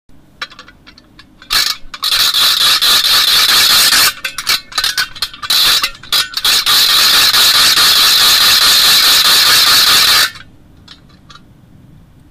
Halloween noisemakers